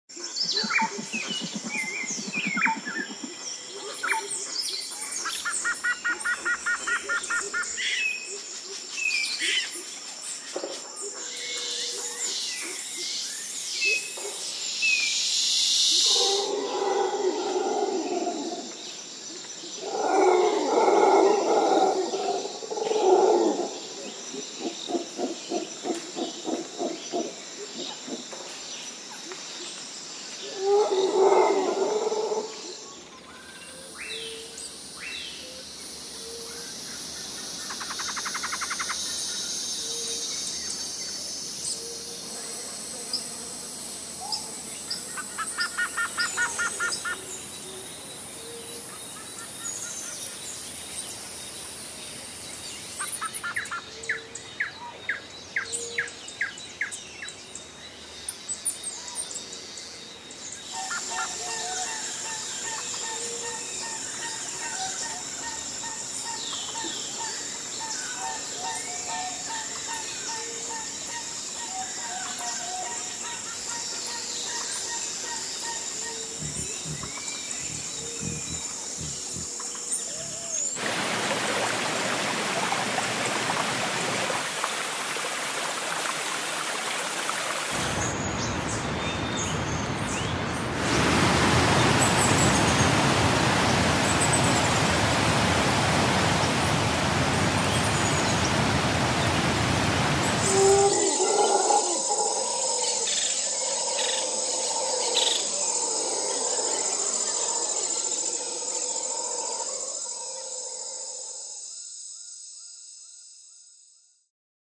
Audio - Nature_s Sound.mp3